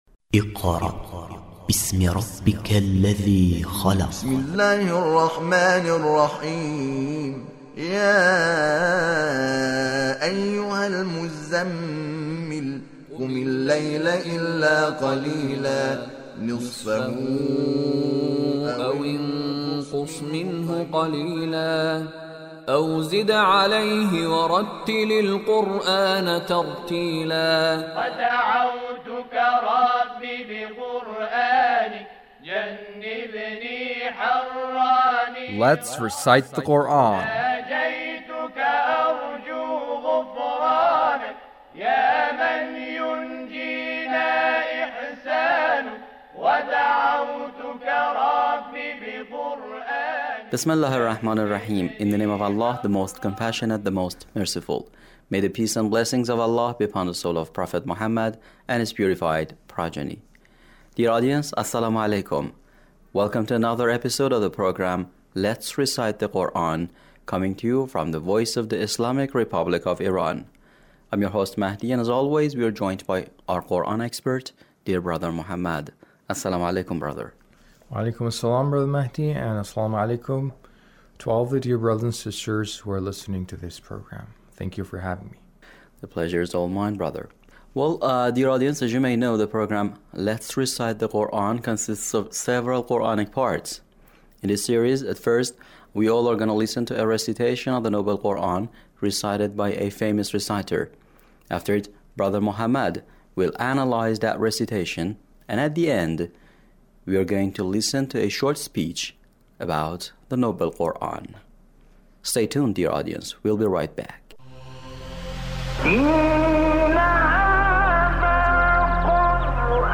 Recitation of Kamil Yusuf Al-Bahtimi